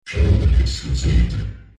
These were made using the vocoder in FL studio, and edited again in Audacity.
A bit fuzzy, but they can be very useful for campaign-mods.